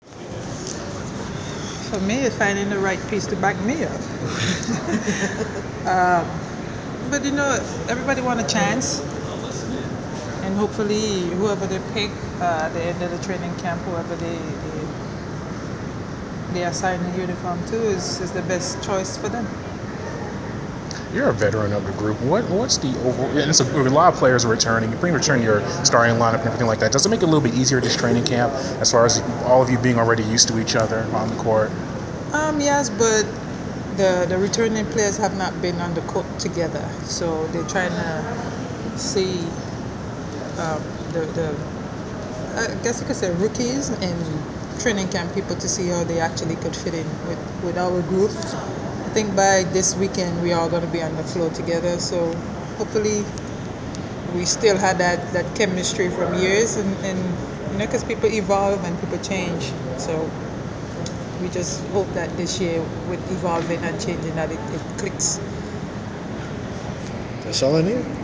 Inside the Inquirer: Interview with Atlanta Dream player Sancho Lyttle
The Sports Inquirer caught up with Atlanta Dream post player Sancho Lyttle during her team’s media day festivities earlier this week.